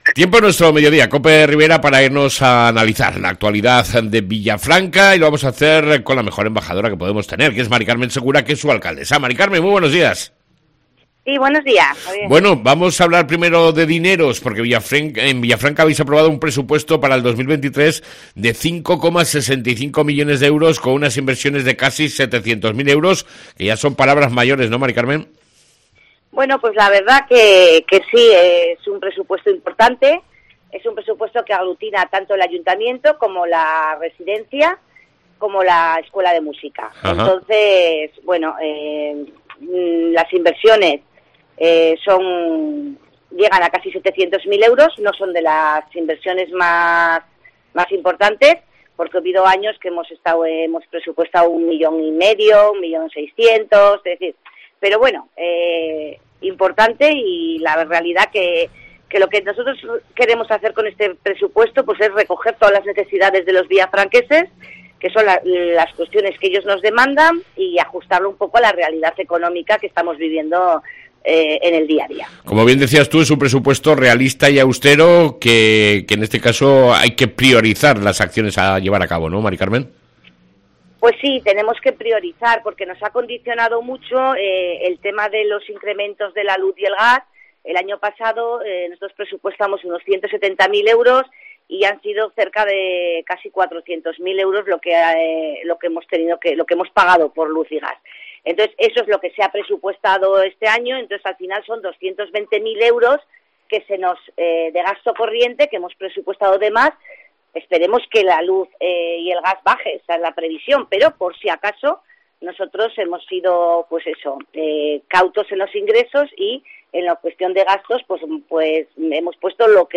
ENTREVISTA CON LA ALCALDESA DE VILLAFRANCA, Mª CARMEN SEGURA